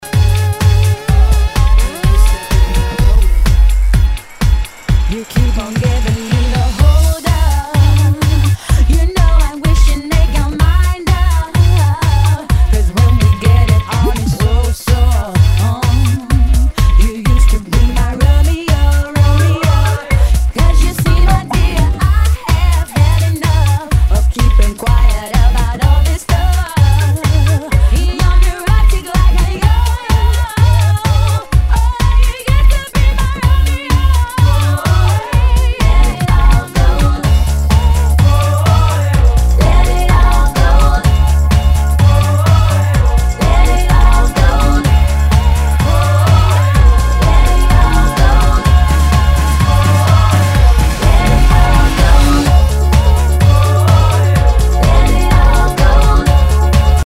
HOUSE/TECHNO/ELECTRO
ナイス！ファンキー・ハウス！